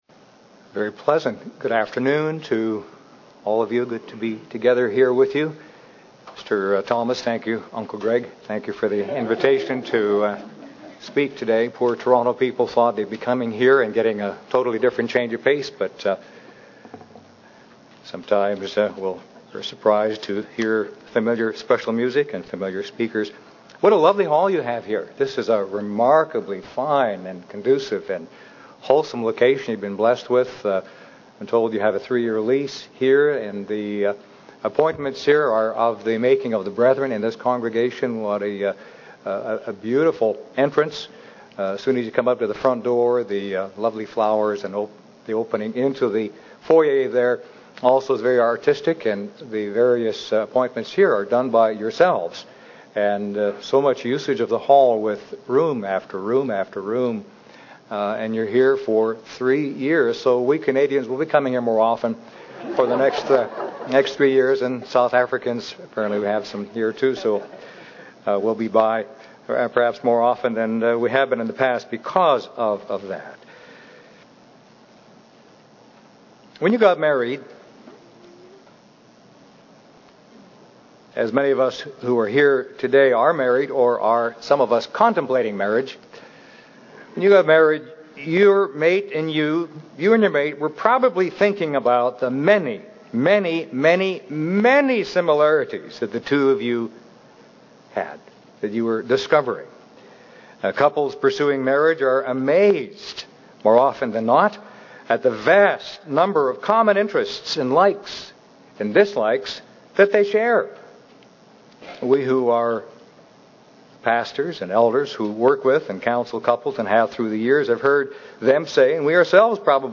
God created differences in us for a purpose and they can build a better marriage and a better Church. This sermon will help you to see that variety can be the spice of life.